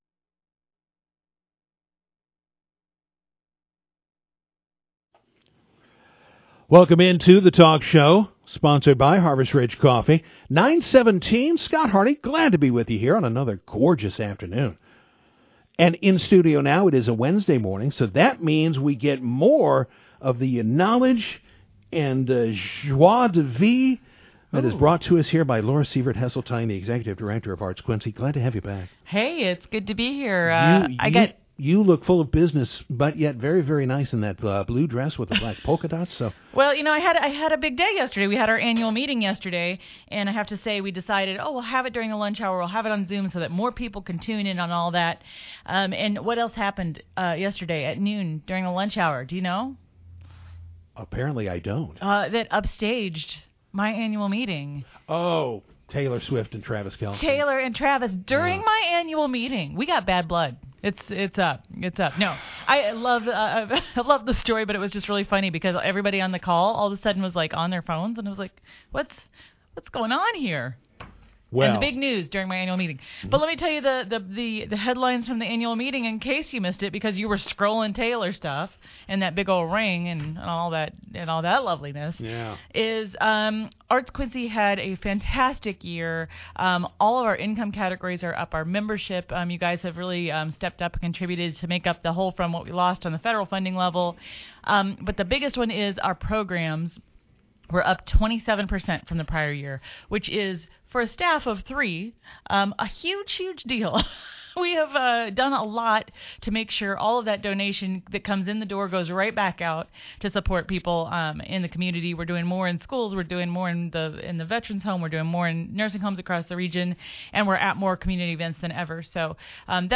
WTAD News Talk Radio